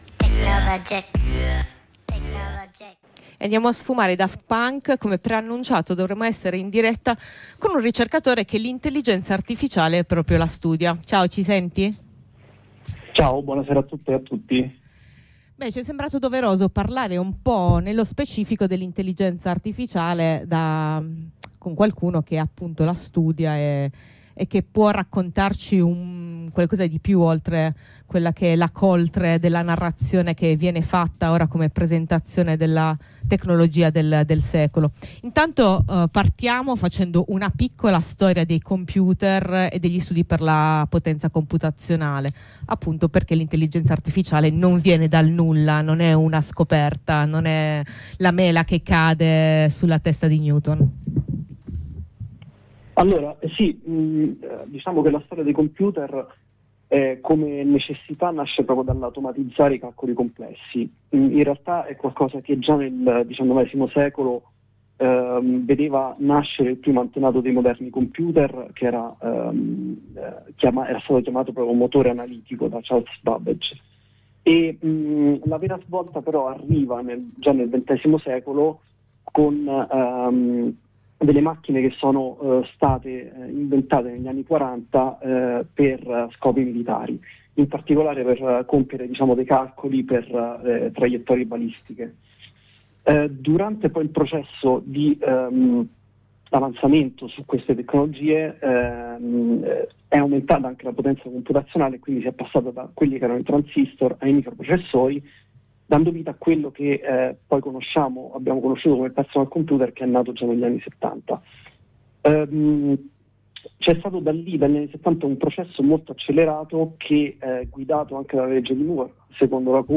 E poi, cosa tecnicamente è, al di là del filtro del marketing e dell’ideologia del progresso? Contestualizzando lo sviluppo dei “cervelli sintetici” all’interno della produzione di merci e infrastrutture digitali, nonché delle visioni del mondo e dei regimi di verità che ne derivano, a Macerie su Macerie cerchiamo di investigare con un ricercatore del campo computazionale la programmazione, gli ambiti attuativi e gli scopi di questa architettura di potere ben poco immateriale e astratta.